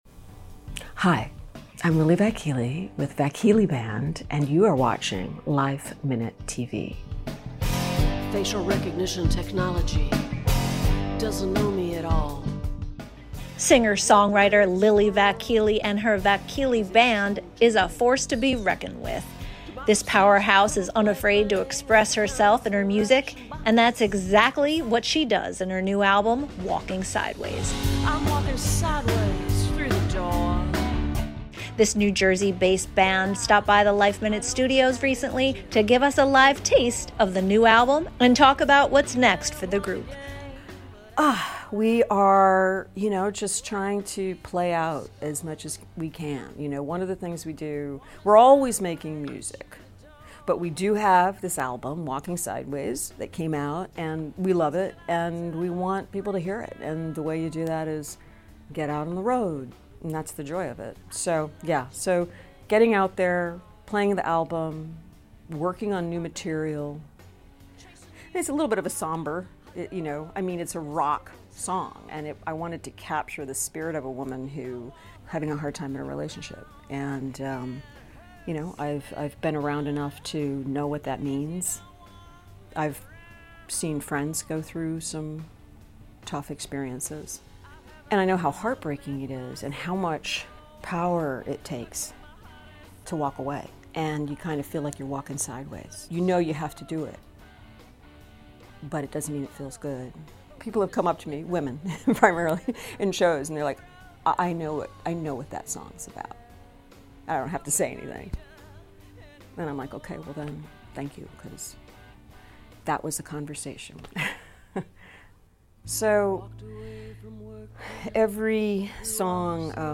guitarist